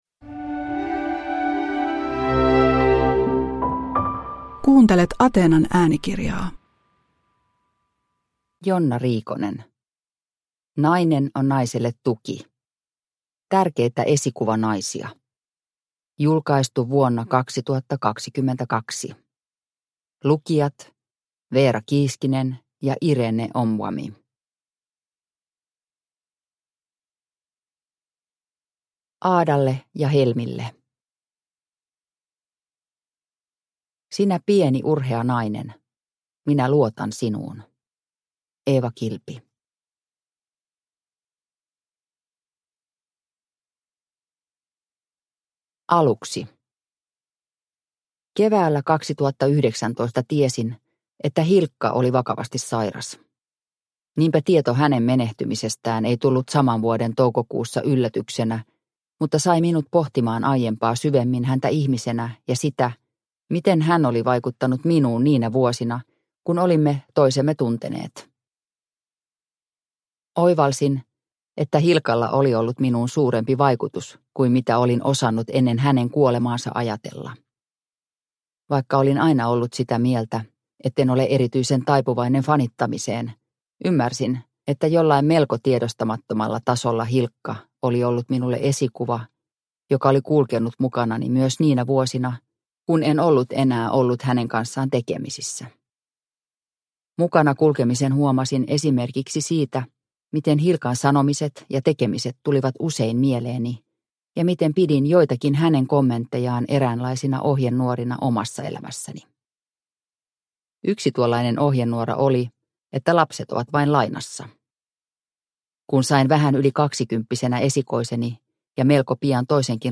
Nainen on naiselle tuki – Ljudbok – Laddas ner